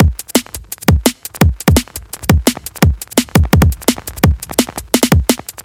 紧张的黑胶DnB鼓
描述：非常紧凑和快节奏的Jungle/Neuro/DnB鼓循环，其中有一些乙烯基的噼啪声。
Tag: 170 bpm Drum And Bass Loops Drum Loops 972.97 KB wav Key : Unknown